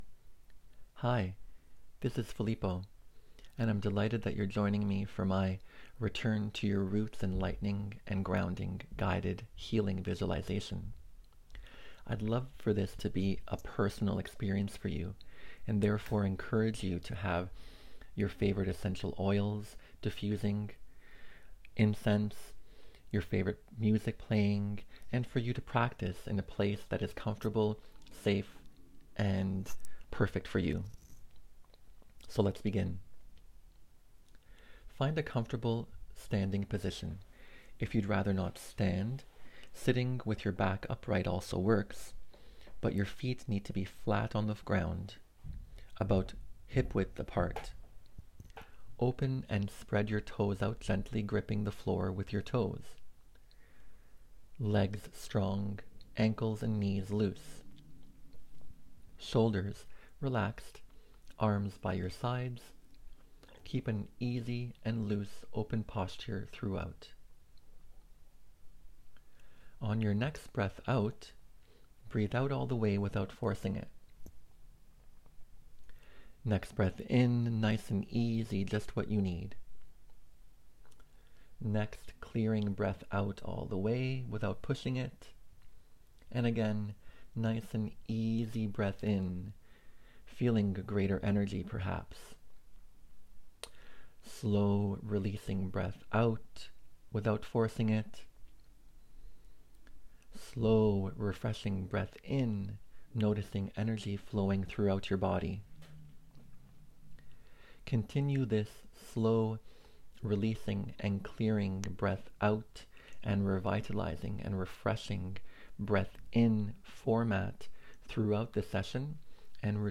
The following video and audio files are taken from our most recent in person workshop, TRANSFORMATION TOOLKIT 1.3: Fall Forward ( Saturday, October 6th) .